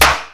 INSTCLAP12-R.wav